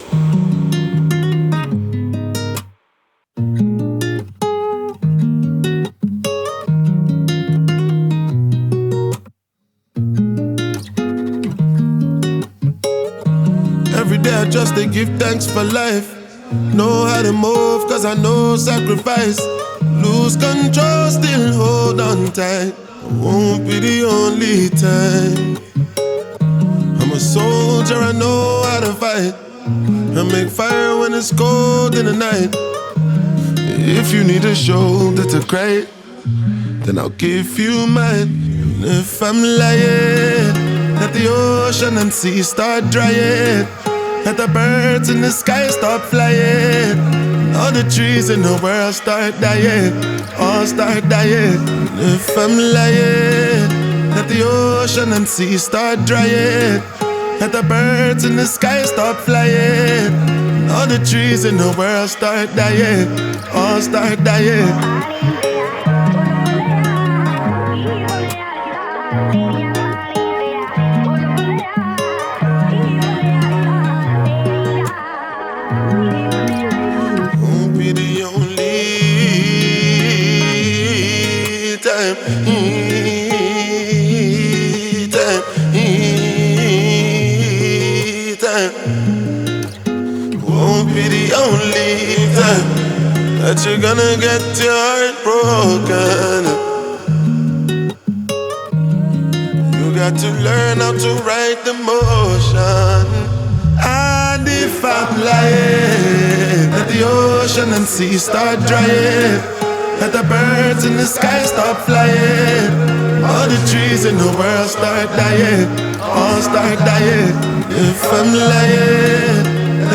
Afrobeats
gbedu